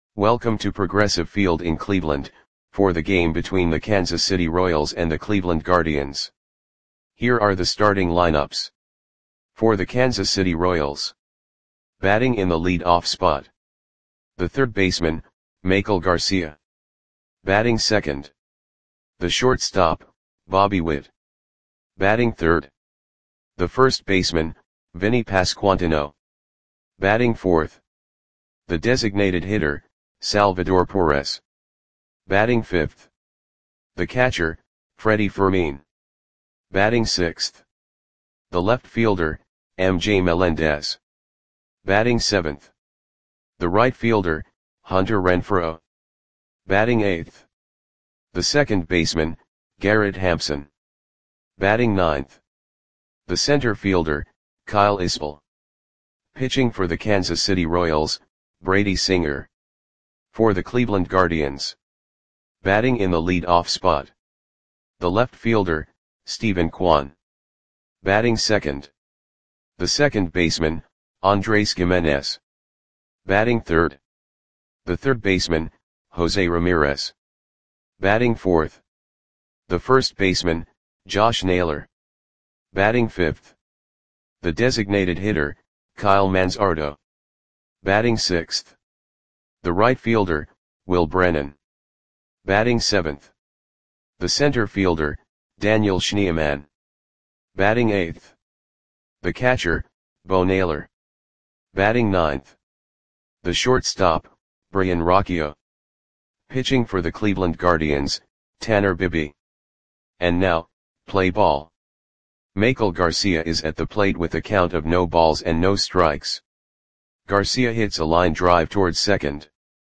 Lineups for the Cleveland Indians versus Kansas City Royals baseball game on June 6, 2024 at Progressive Field (Cleveland, OH).
Click the button below to listen to the audio play-by-play.